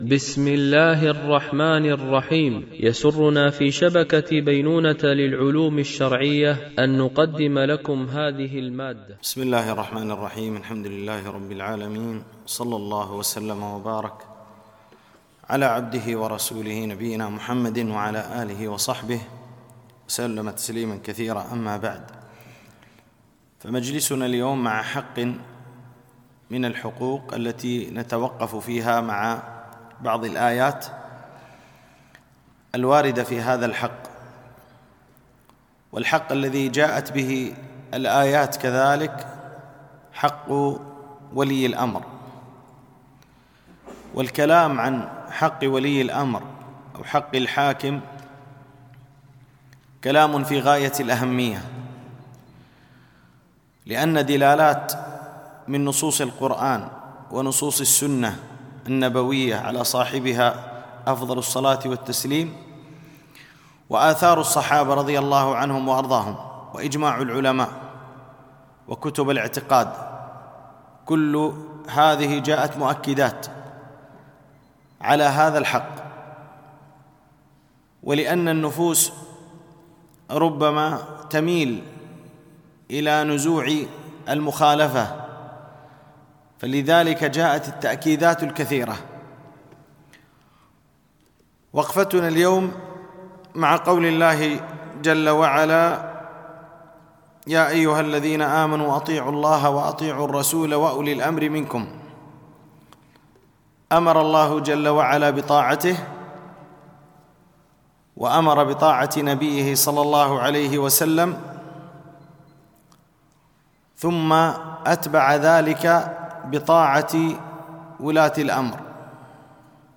وقفات مع آيات ـ الدرس 05 ( حق ولي الأمر )